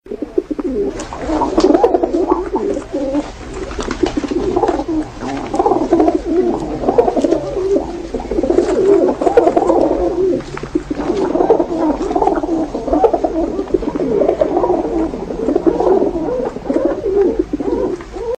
Звуки голубей